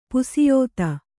♪ pusiyōta